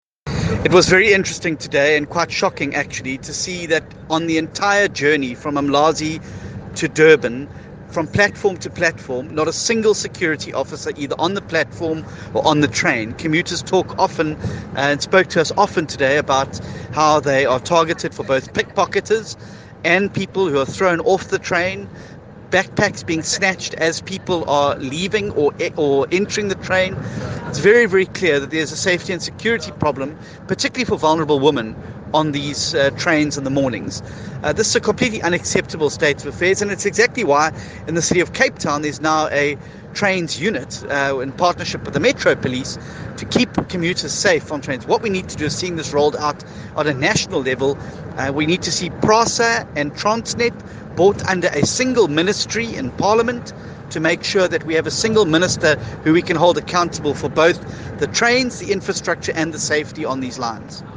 Please find attached soundbites in English by